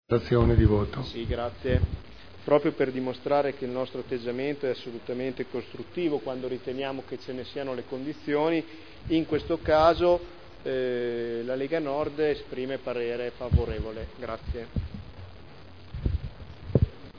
Nicola Rossi — Sito Audio Consiglio Comunale
Seduta del 13/12/2010 Deliberazione: Aggregazione sezione audiolesi scuola secondaria di 1° grado Lanfranco dichiarazioni di voto